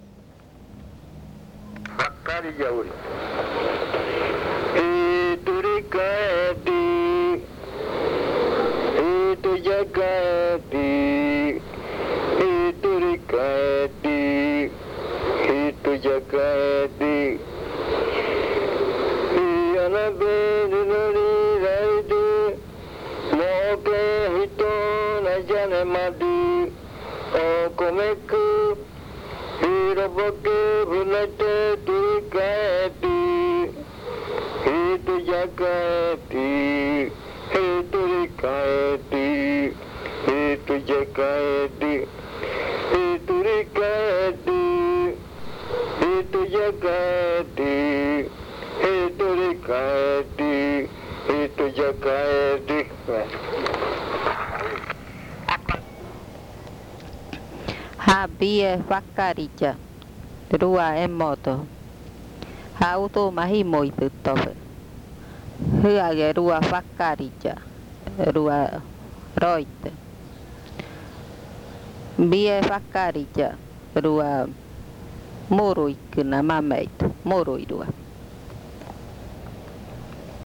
Leticia, Amazonas
Cancion hablada (uuriya rua). Sátira.
Spoken chant (uuriya rua).
Cantos de yuakɨ